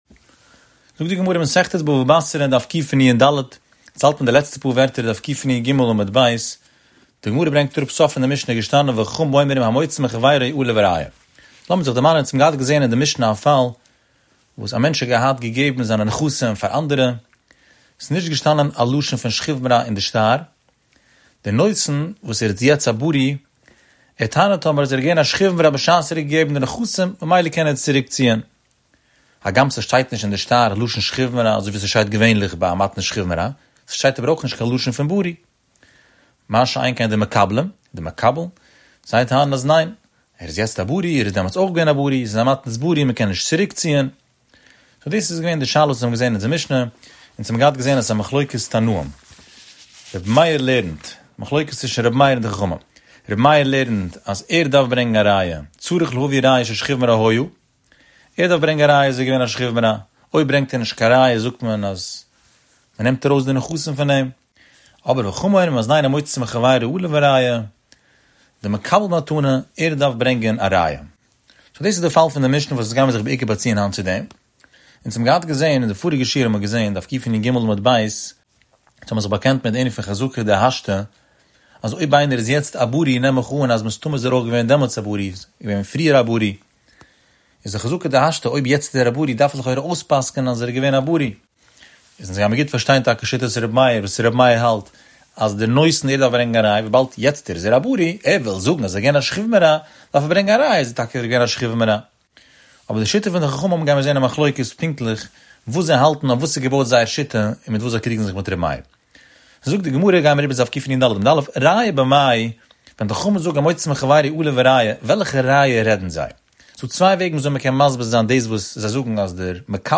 The daily daf shiur has over 15,000 daily listeners.